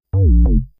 coffee_out.wav